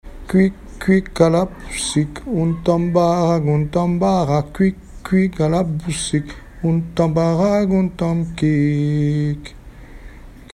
Mémoires et Patrimoines vivants - RaddO est une base de données d'archives iconographiques et sonores.
enfantine : comptine
Pièce musicale inédite